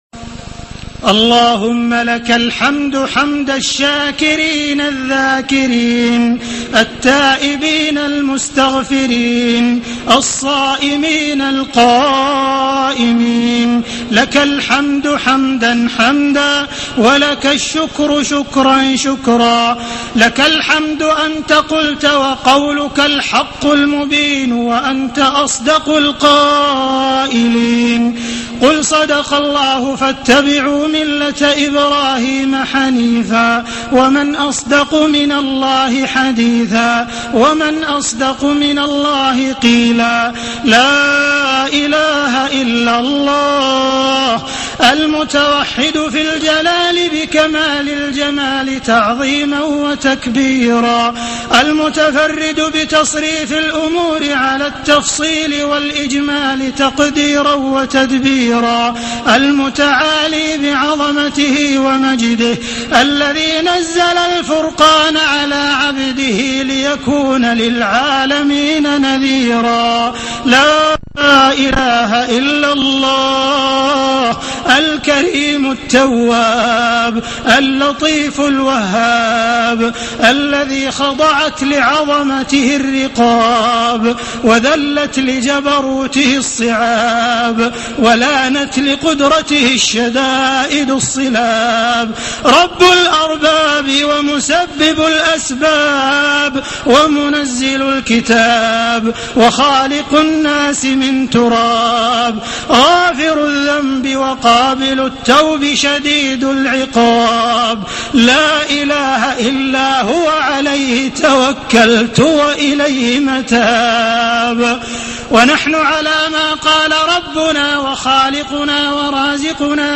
دعاء ختم القرآن
المكان: المسجد الحرام الشيخ: معالي الشيخ أ.د. عبدالرحمن بن عبدالعزيز السديس معالي الشيخ أ.د. عبدالرحمن بن عبدالعزيز السديس دعاء ختم القرآن